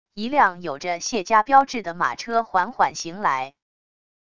一辆有着谢家标志的马车缓缓行来wav音频生成系统WAV Audio Player